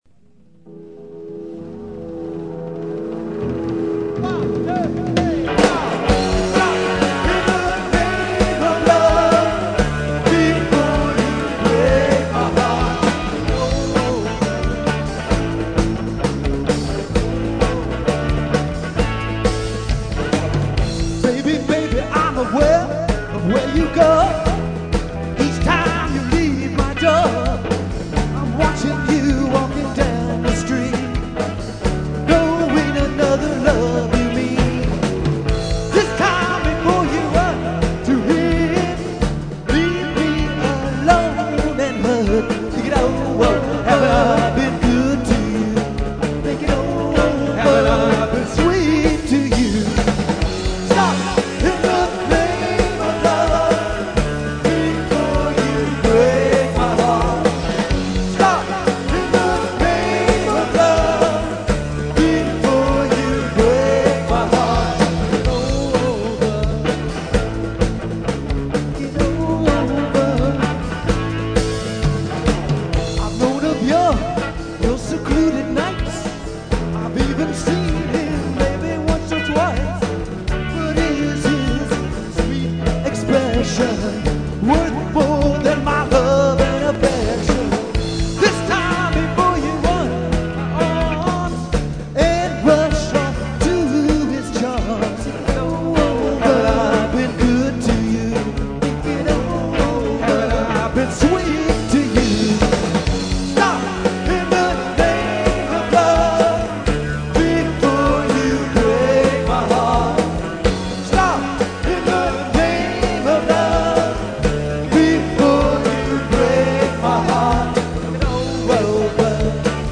ROCK 'N ROLL